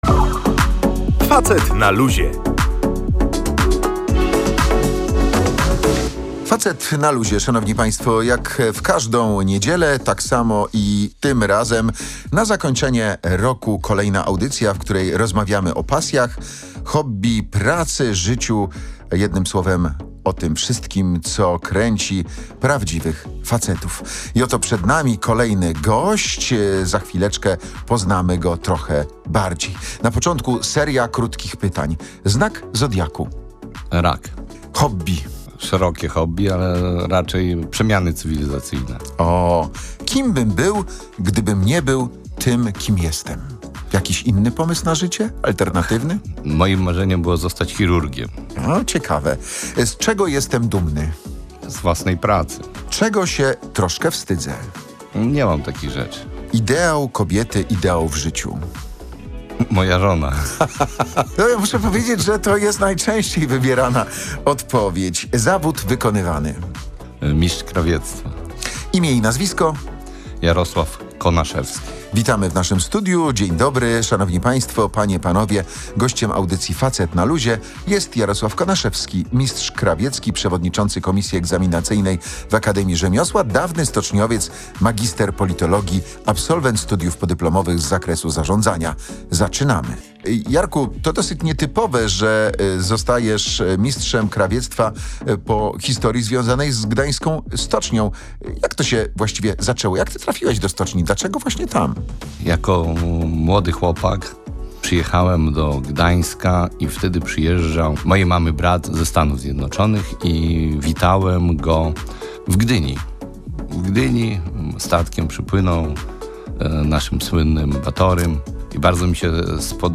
O polityce, sytuacji polskiego rzemiosła i kaprysach klientów opowiedział w niedzielnej audycji.